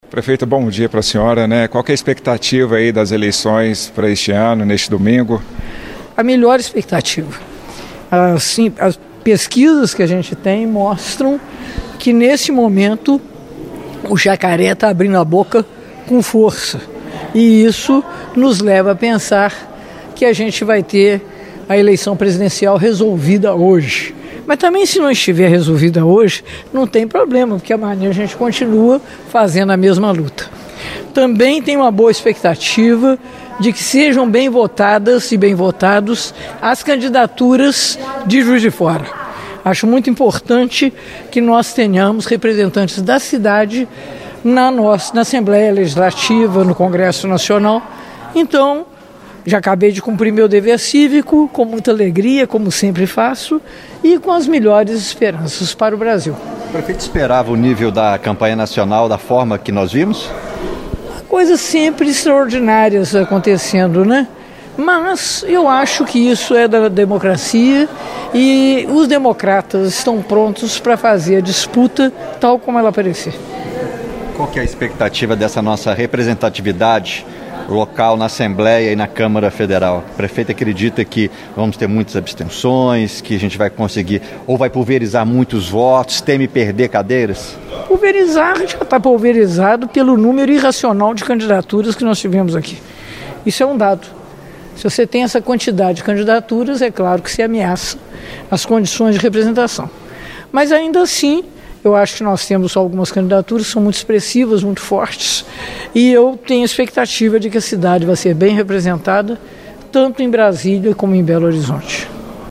A prefeita de Juiz de Fora, Margarida Salomão (PT) votou nesta manhã no Colégio Machado Sobrinho. Em entrevista à imprensa, ela disse acreditar em uma vitória de Lula já no primeiro turno e avaliou que, pelo número de candidaturas, a representatividade de Juiz de Fora será pulverizada.